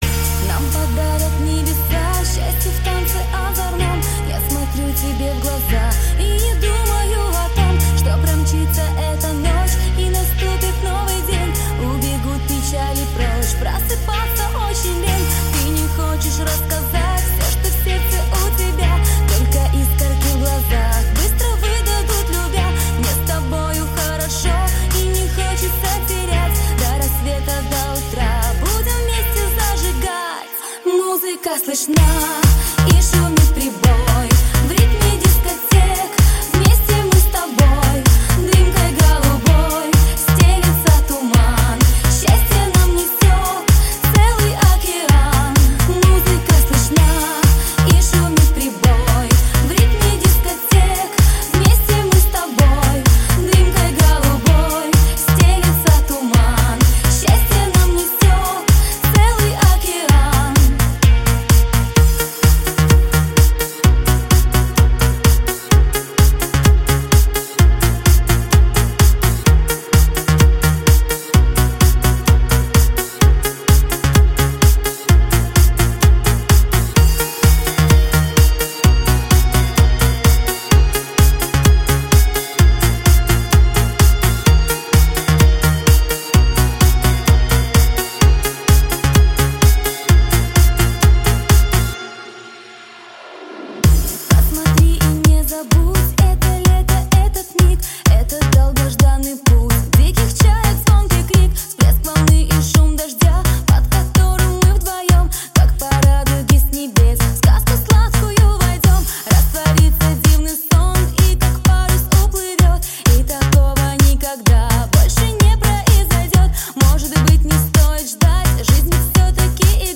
dance песни
русская танцевальная музыка